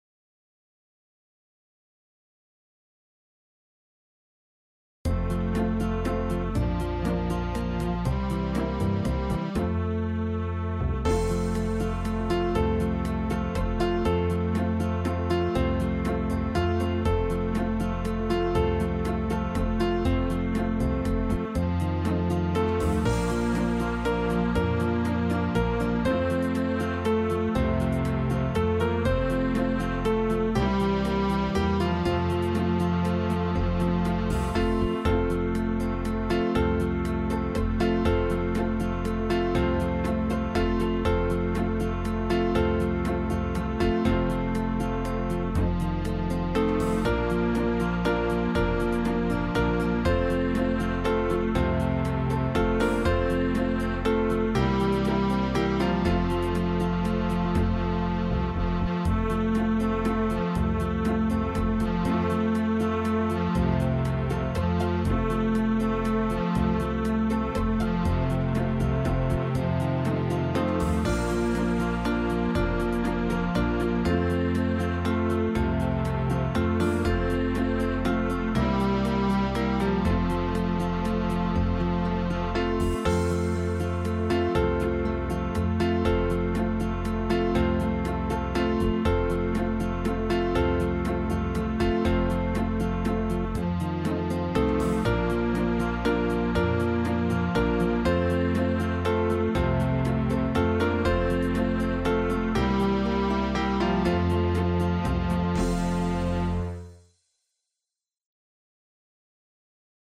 16-beat intro.
This song is in 3/4 waltz time.